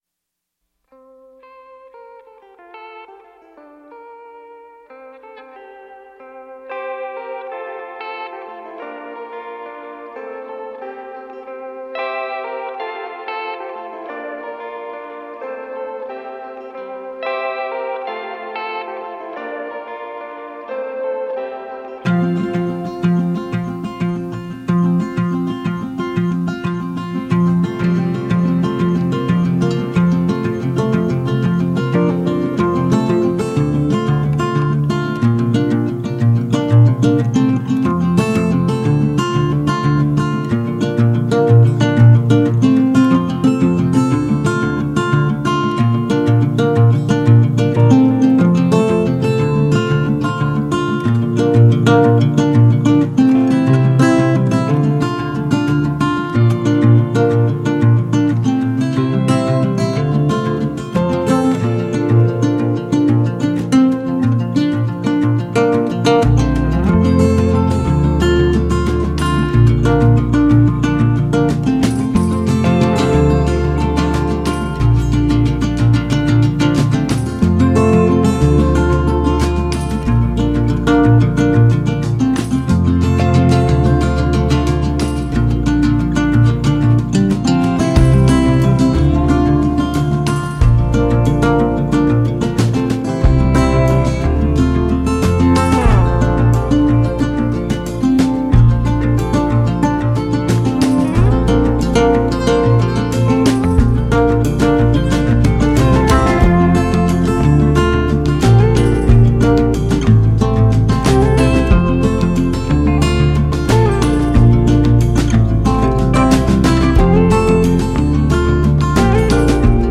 Kids Audio Bible Stories | People of the Bible | Ruth